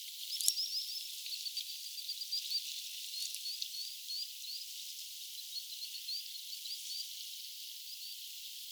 nuori rantasipi?
olisiko_nuoren_rantasipin_aania.mp3